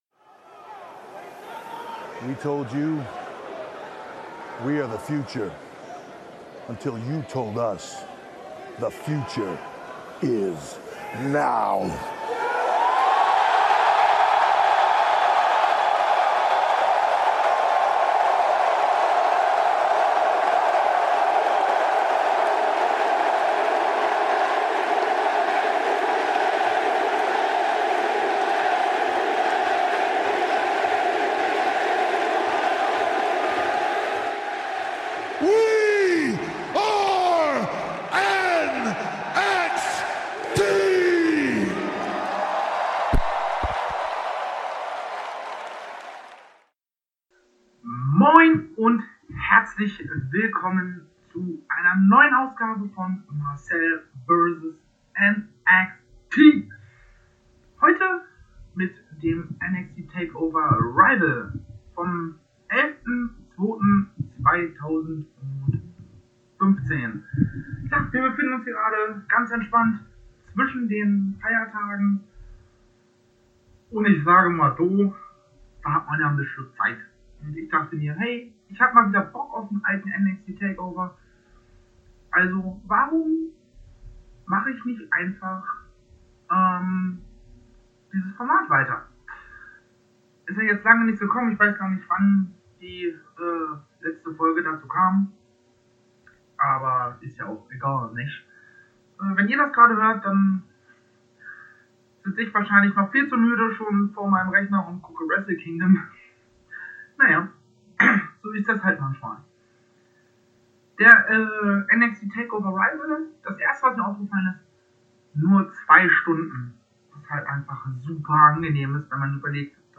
*Sorry für die wacke Soundqualität.